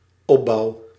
Ääntäminen
France: IPA: [kɔ̃.pɔ.zi.sjɔ̃]